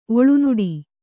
♪ oḷunuḍi